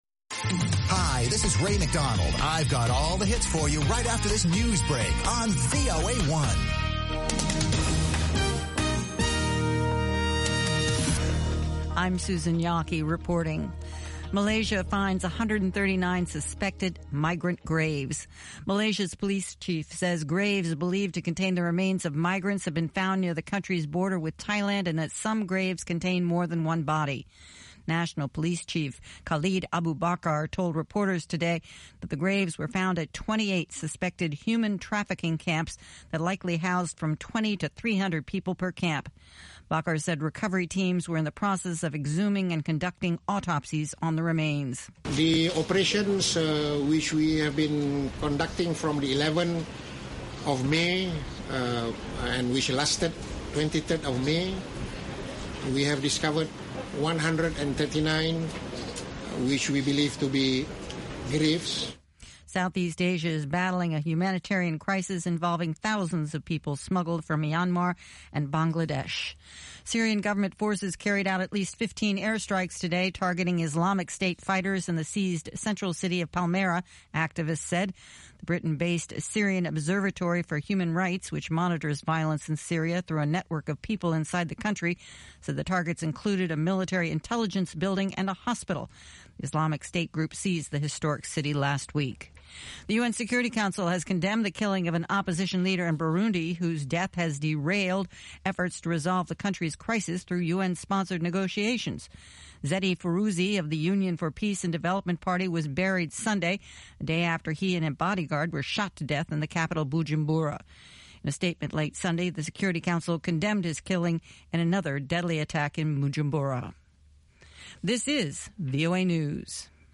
pan-African music